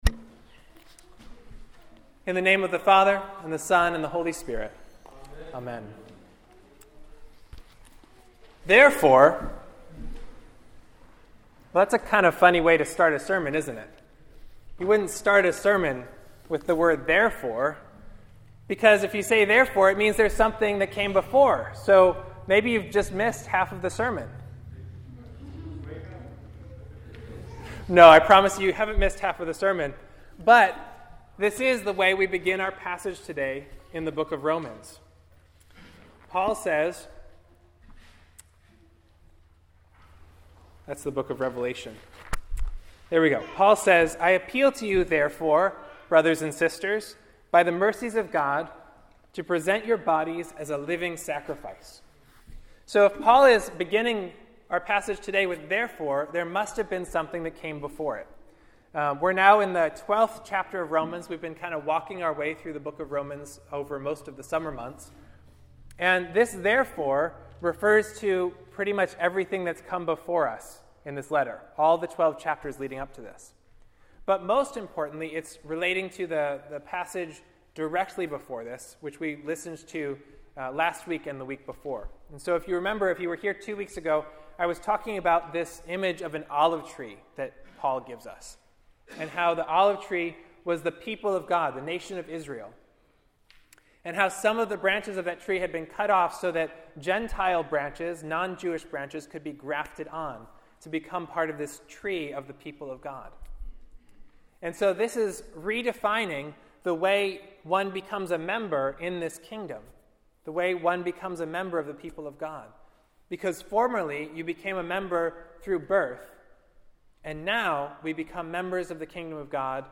Therefore…is that a good way to begin sermon?